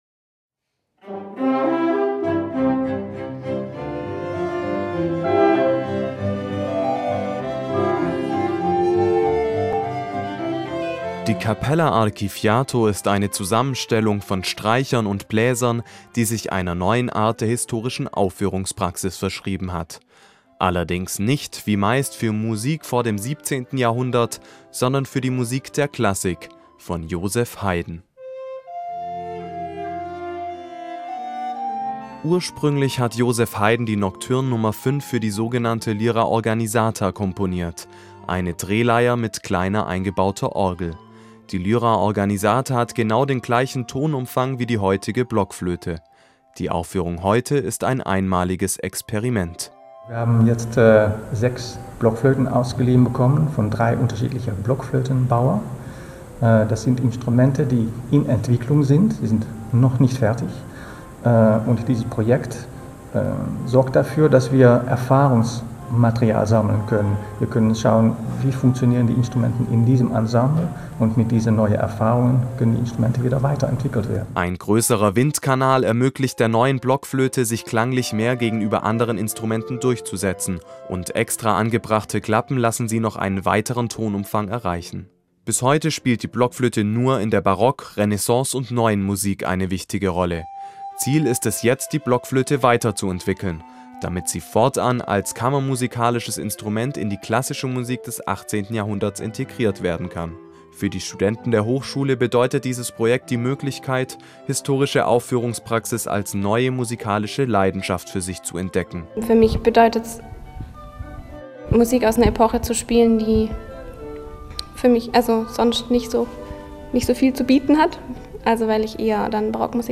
Die Hochschule für Musik in Karlsruhe ist immer wieder eine Spielwiese für neue klangliche Experimente. Wir waren bei einer Aufführung des Ensembles Capella Archifiato